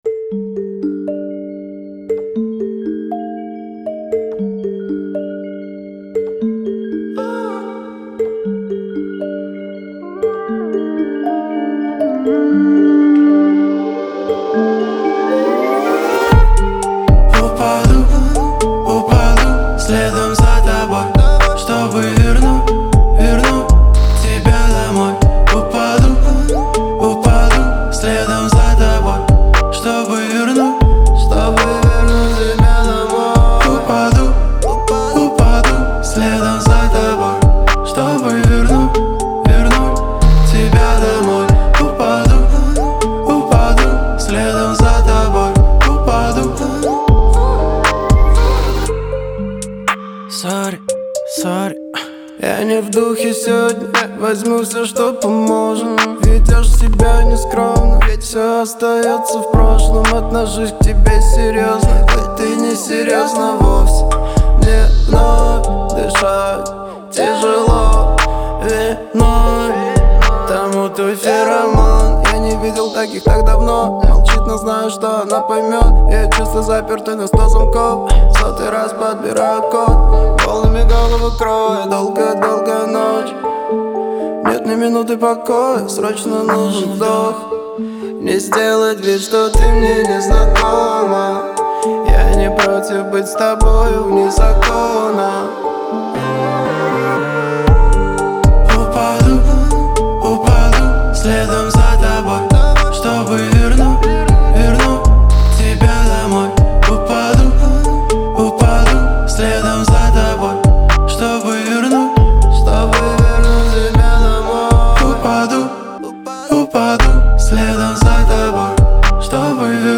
это трек в жанре альтернативного рока